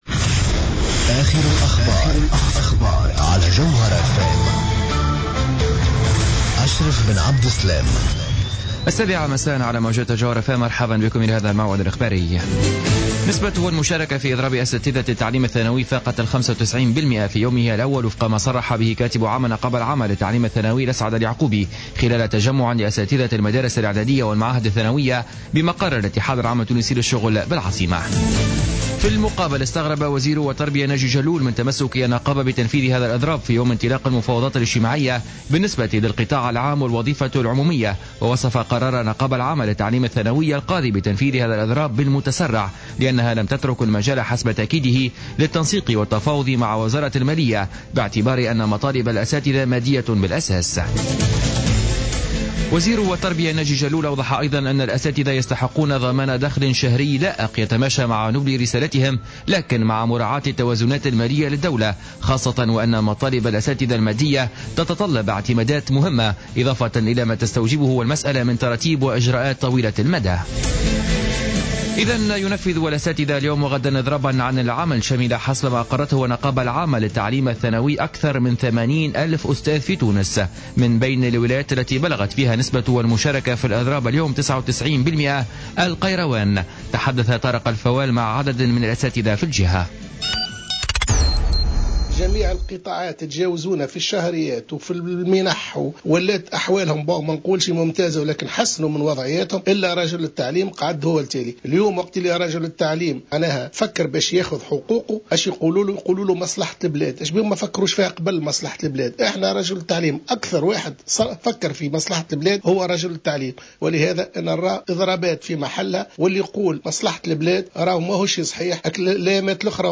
نشرة أخبار السابعة مساء ليوم الثلاثاء 17 فيفري 2015